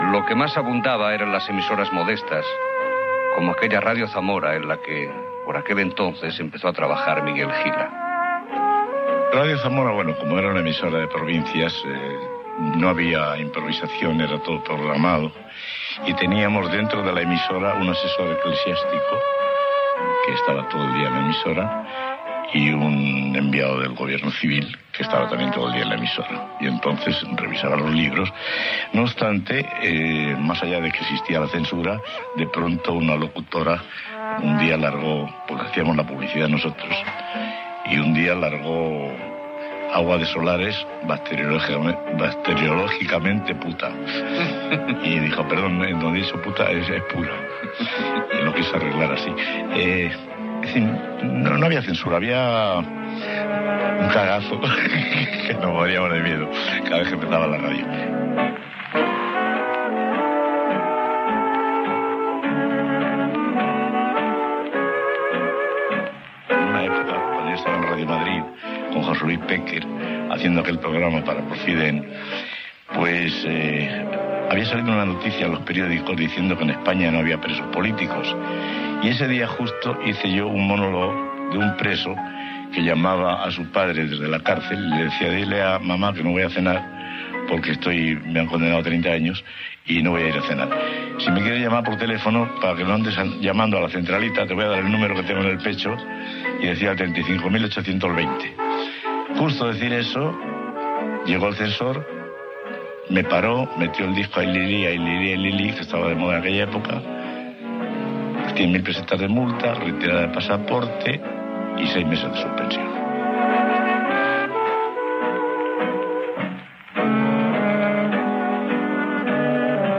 L'humorista Miguel Gila parla dels seus inicis a Radio Zamora i més tard a Radio Madrid i el locutor Luis Arribas Castro parla de l'emissora on va començar
Divulgació